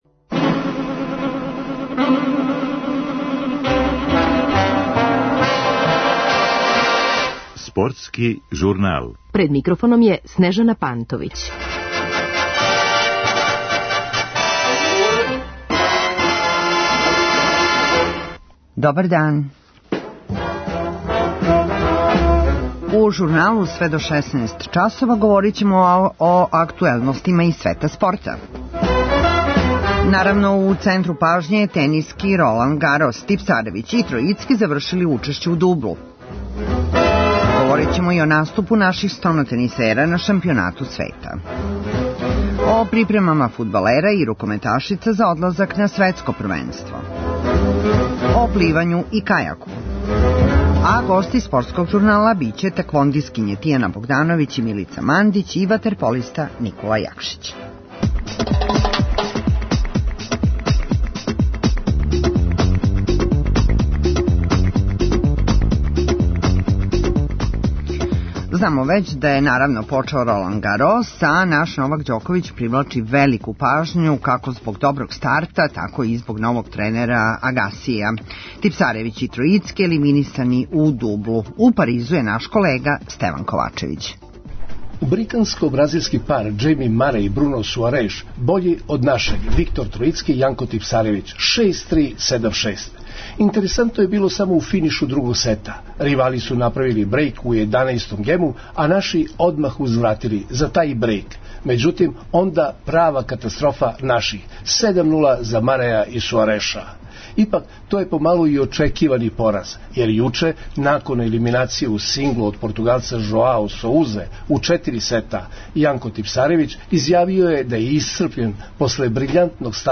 одакле се јавља наш репортер